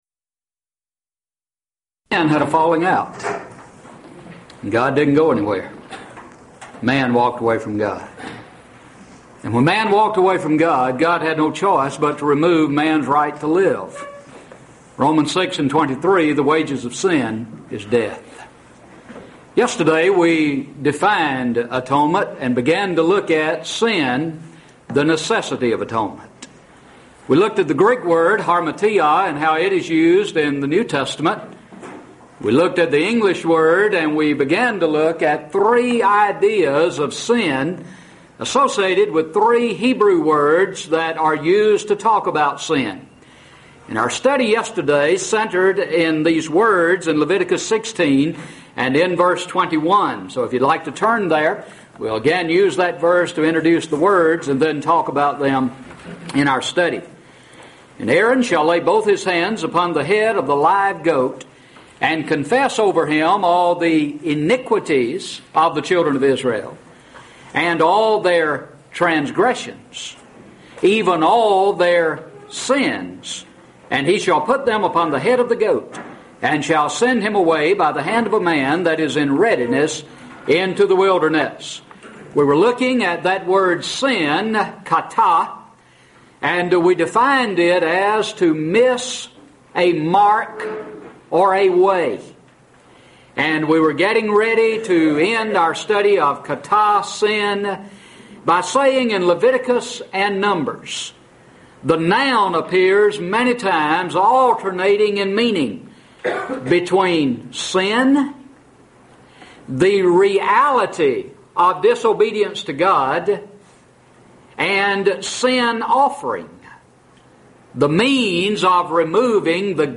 Event: 1998 East Tennessee School of Preaching Lectures Theme/Title: Studies in the Book of Leviticus
lecture